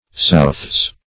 southsay - definition of southsay - synonyms, pronunciation, spelling from Free Dictionary Search Result for " southsay" : The Collaborative International Dictionary of English v.0.48: Southsay \South"say`\, v. i. See Soothsay .